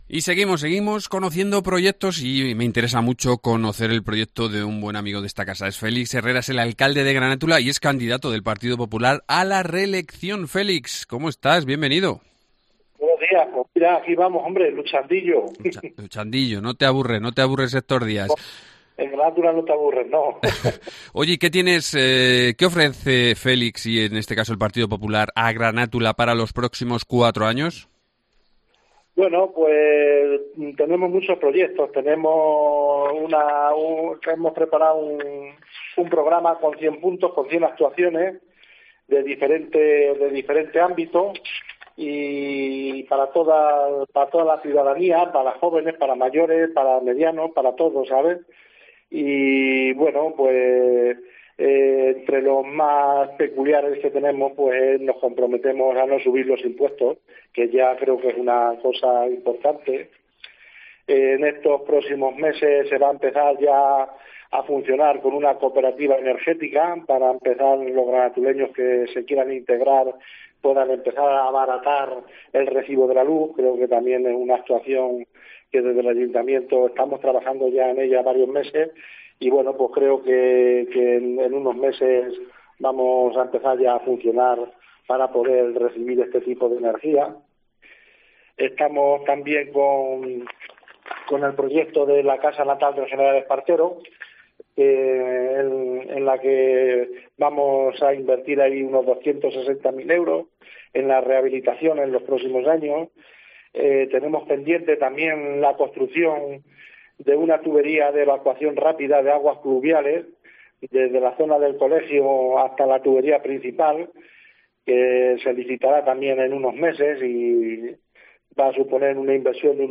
Entrevista
Félix Herrera, alcalde y candidato a la reelección en Granátula de Calatrava